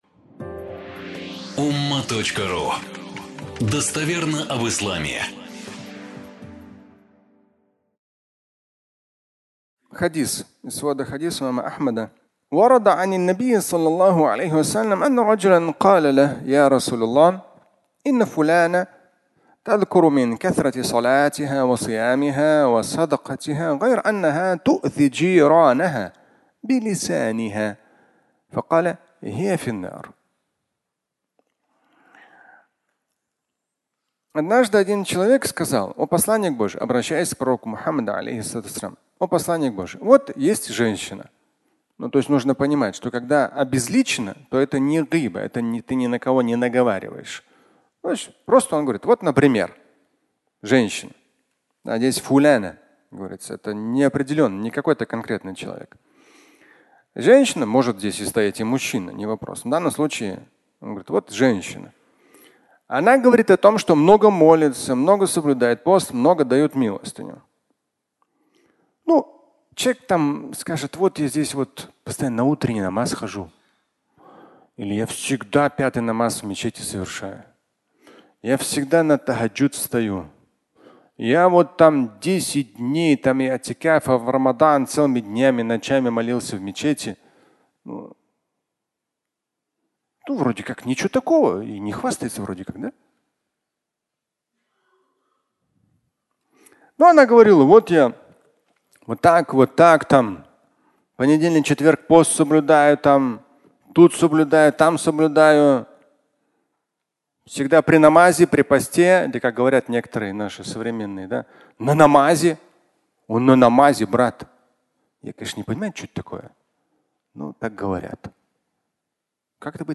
Кусочек сыра (аудиолекция)